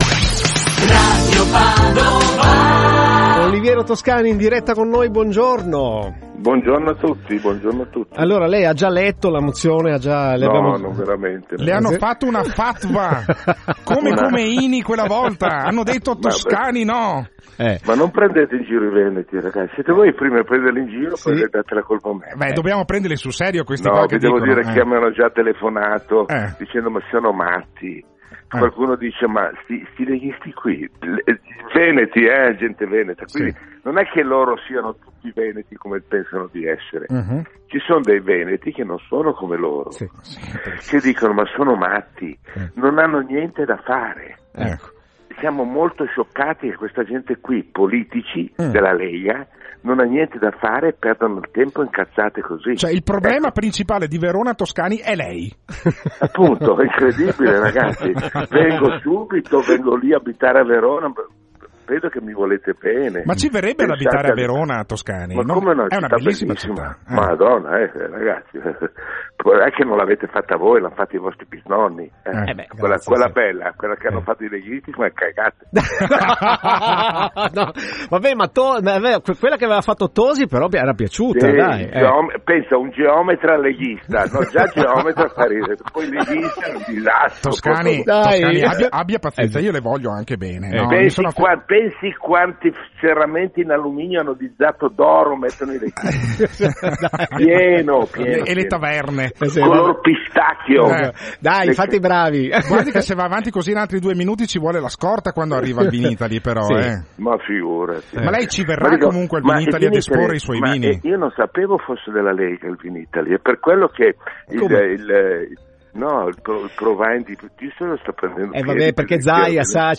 Qui sotto la registrazione dell’intervento telefonico di Oliviero Toscani a Radio Padova